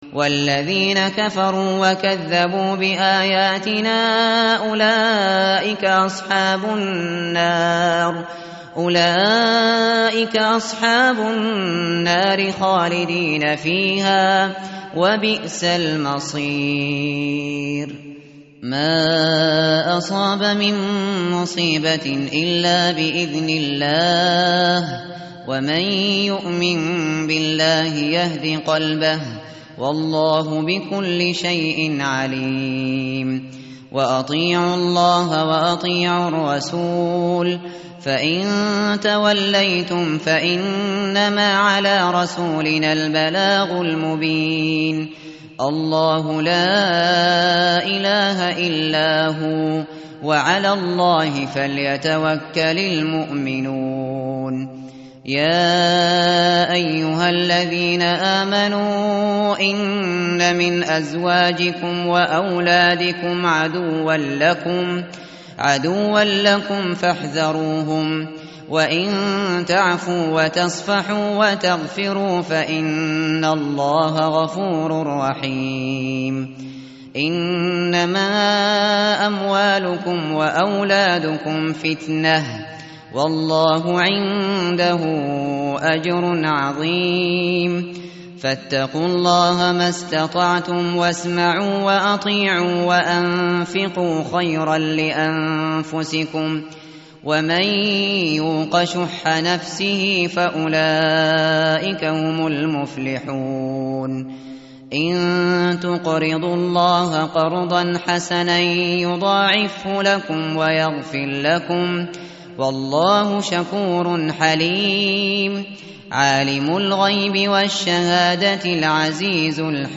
tartil_shateri_page_557.mp3